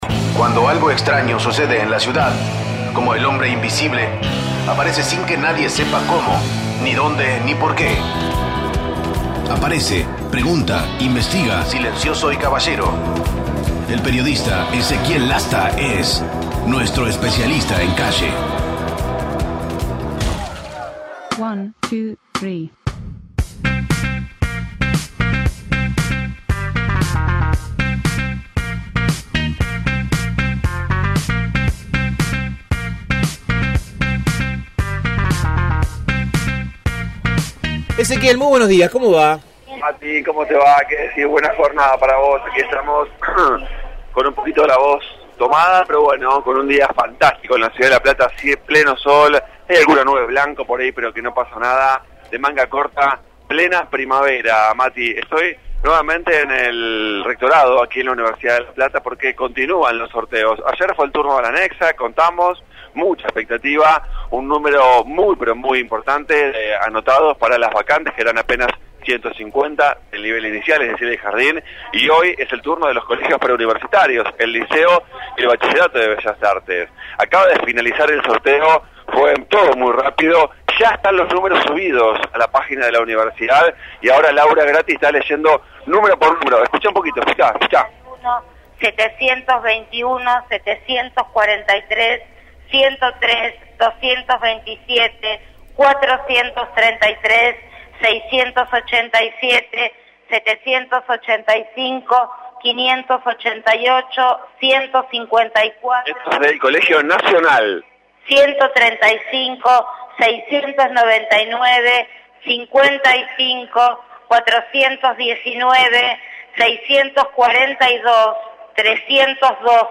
MÓVIL/ Sorteo de vacantes colegios UNLP